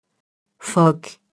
phoque [fɔk]